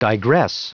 Prononciation du mot digress en anglais (fichier audio)
Prononciation du mot : digress